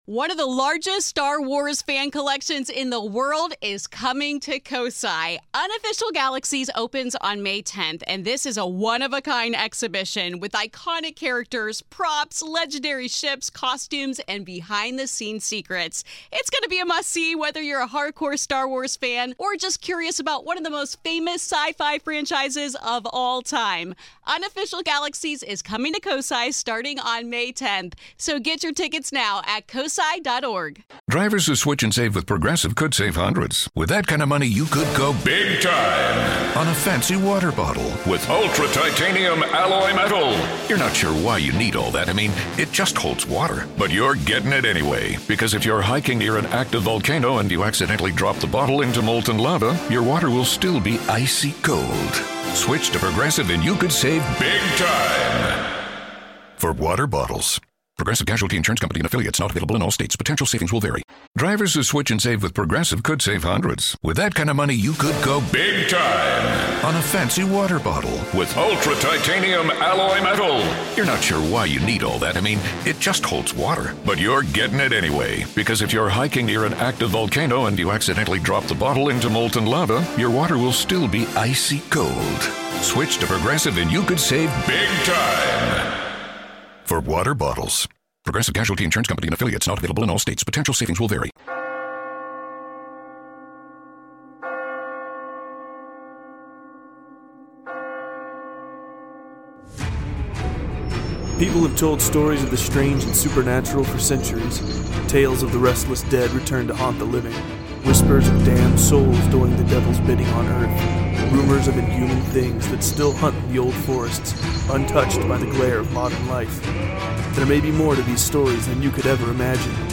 Bonus Live show, Conspiracies, join us for the next one!
In this episode we jumped on facebook live for a bit of a laugh, we recorded part of the show, so here you go, but please join the group and join us on the next one 24th of November, the end will also include an ask us anything part, remember you can let us know what you think...